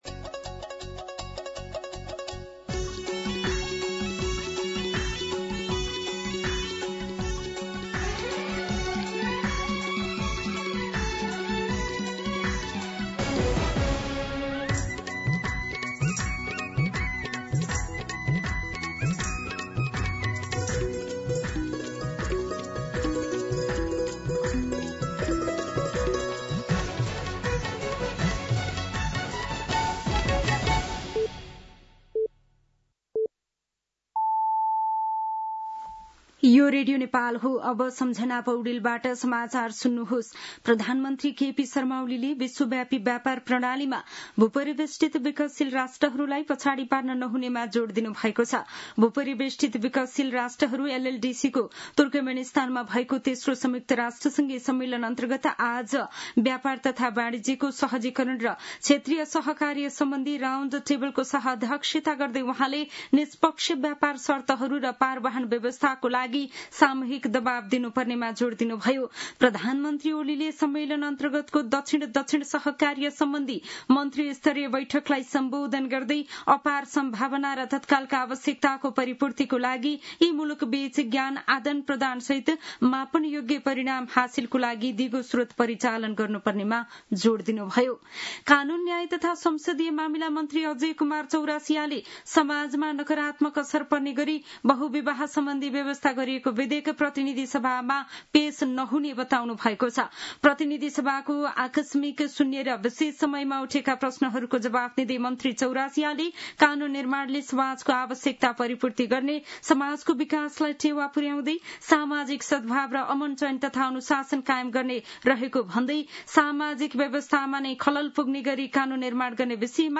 दिउँसो ४ बजेको नेपाली समाचार : २१ साउन , २०८२
4pm-News-04-21.mp3